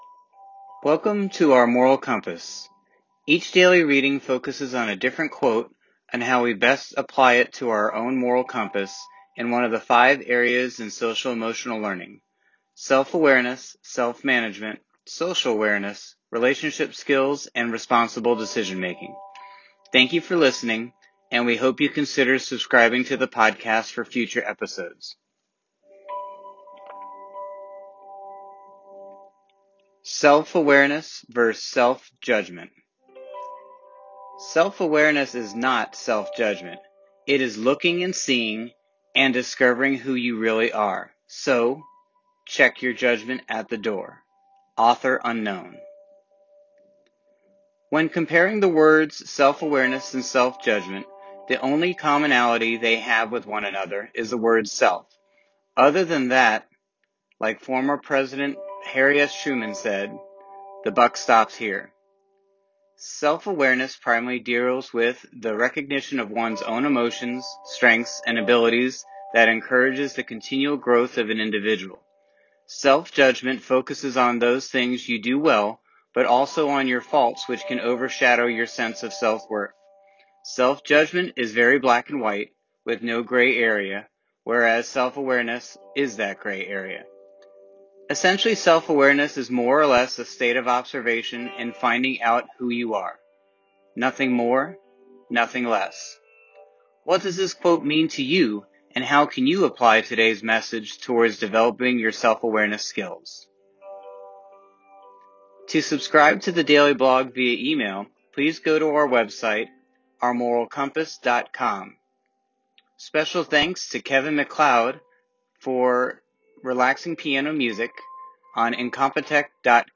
Each daily reading focuses on a different quote on how we can best apply it to our own moral compass and one of the five areas in Social Emotional Learning: Self-Awareness, Self-Management, Social Awareness, Relationship Skills and Responsible Decision Making.